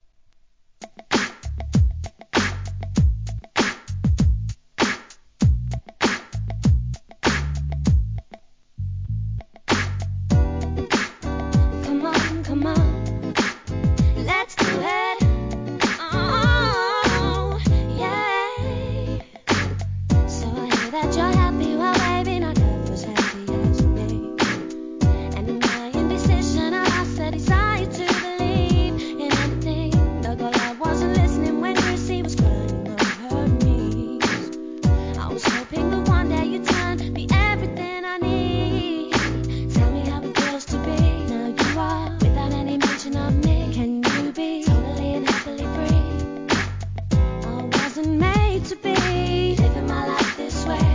HIP HOP/R&B
REGGAE VERSIONに加えてR&B VERSIONを2 VERSION収録、オススメ!